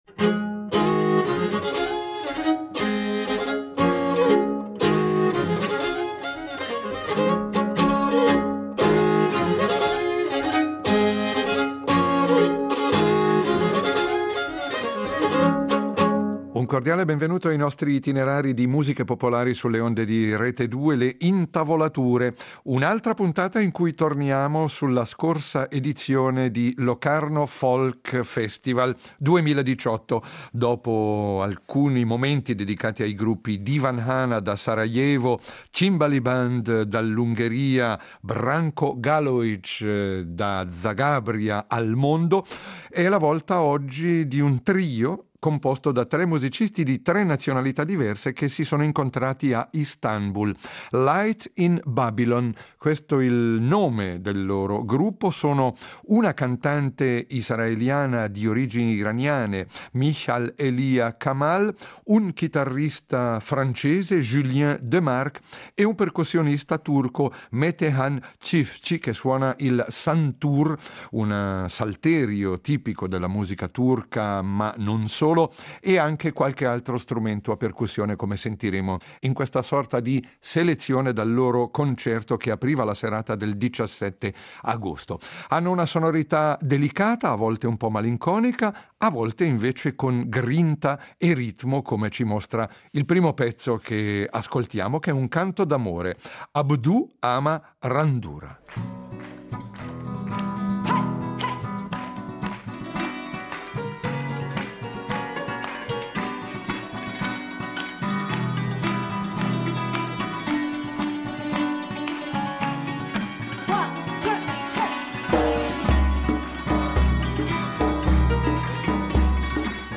Itinerari di musica popolare